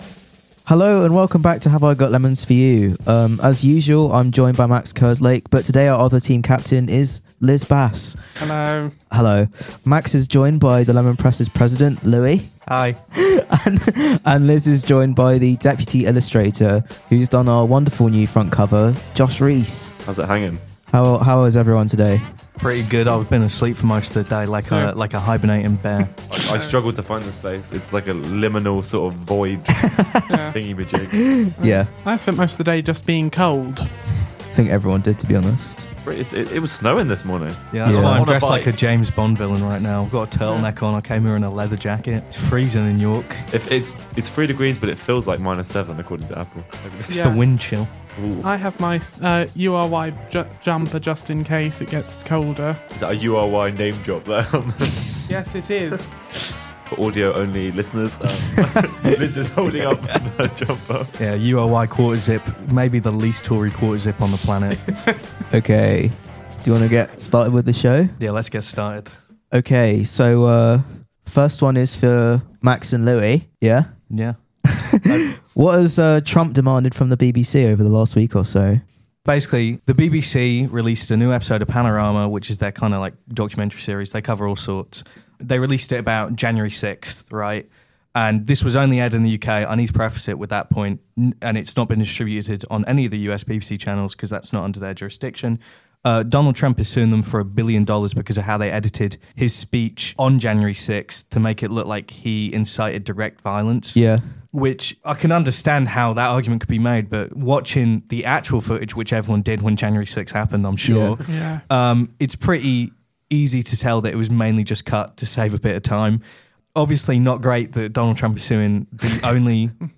The Lemon Press presents its bi-weekly radio show, Have I Got Lemons for You!